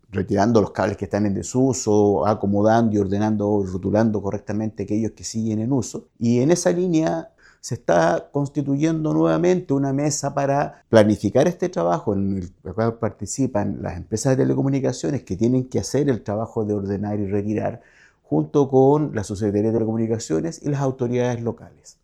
El alcalde subrogante de Los Ángeles, Raúl Fuentes, destacó la importancia de esta iniciativa.